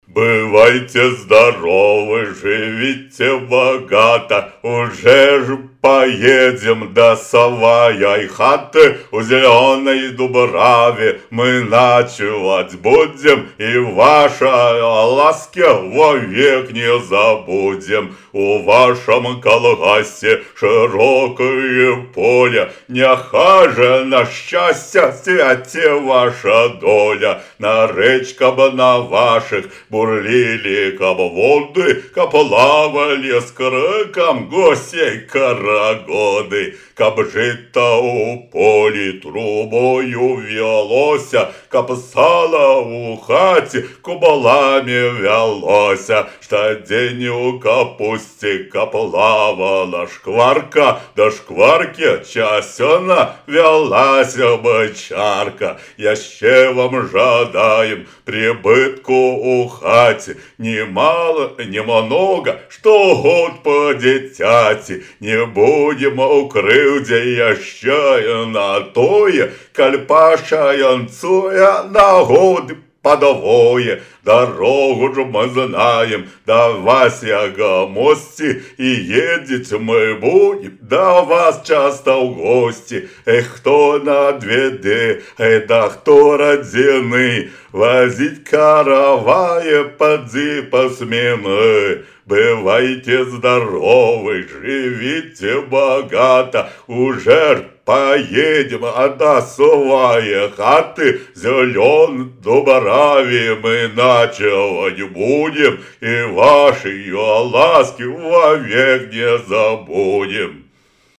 Описание: Моя попытка промычать белорусскую классику.